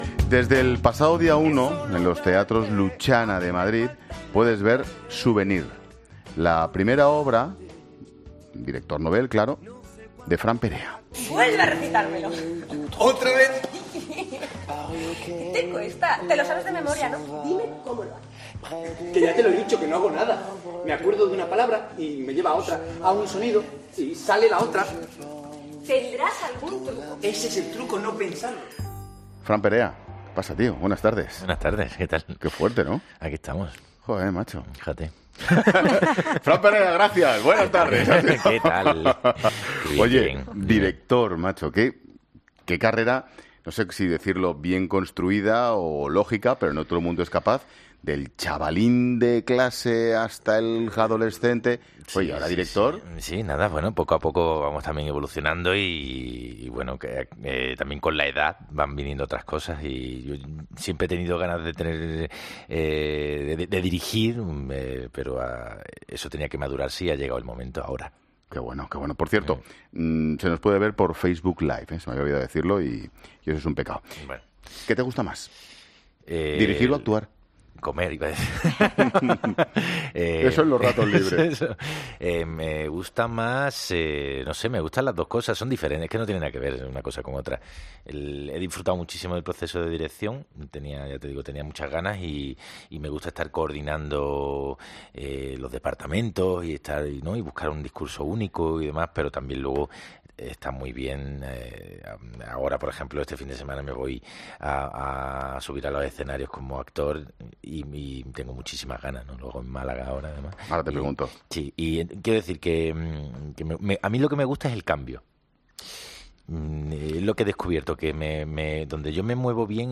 Escucha la entrevista a Fran Perea en 'La Tarde'